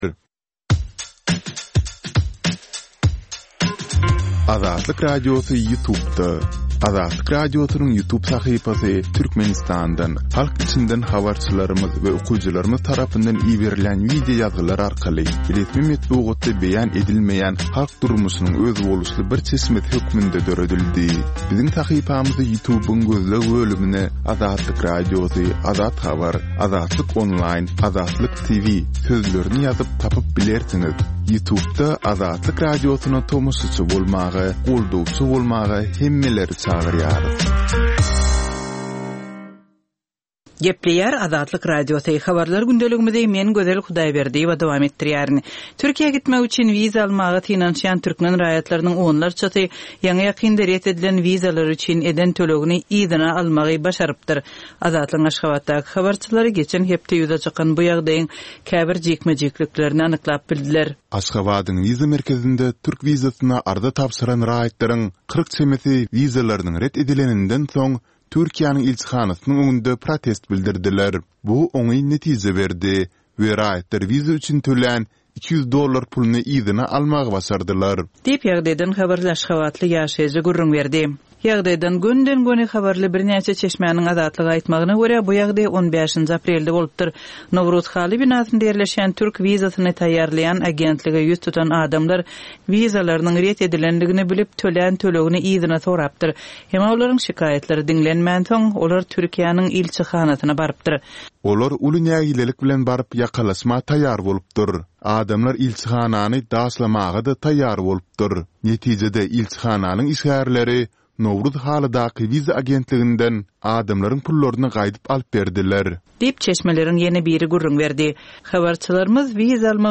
Türkmenistandaky we halkara arenasyndaky soňky möhüm wakalar we meseleler barada ýörite informasion-habarlar programma. Bu programmada soňky möhüm wakalar we meseleler barada giňişleýin maglumatlar berilýär.